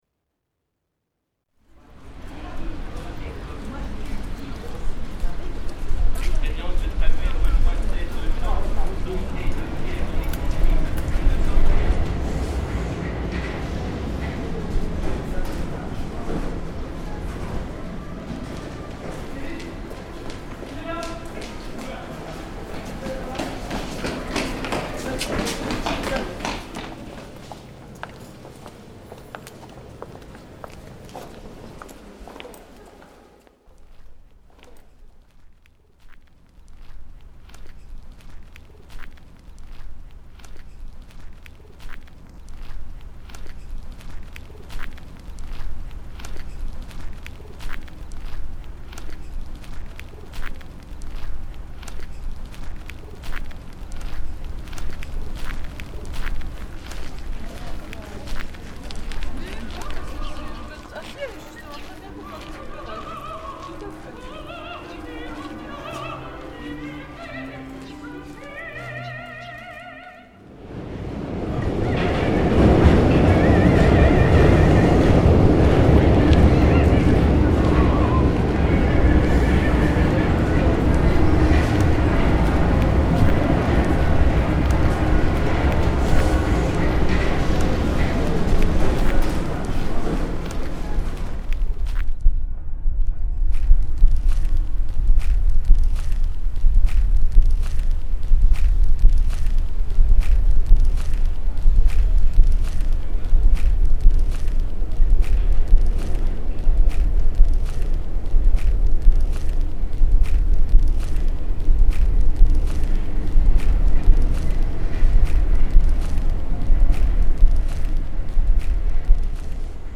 Une scène de marche quotidienne , linéaire, filée à partir de sons extérieurs pris comme correspondants de sons qui surviennent à l’endormissement ou au baillement. On s’ approche de ces phénomènes en en prenant la teinte lointaine, infime, sourde et sifflante.
Les pas du marcheur sont inspirés du pouls dans l’oreille. Le vrombissement du metro et le vent , du tremblement de la pression dû à l’ouverture des trompes d’Eustache, par bâillement. Les cloches , du tintement léger qui peut survenir dans le silence par grande concentration.
Le sifflement est la fondamentale, il traverse tous les rythmes, semble les suivre parfois. Il est chassé brutalement par l’ouverture de l’oreille qui se traduit par la rame qui débouche du tunnel. Il réapparaît plus tard, à un ton plus haut.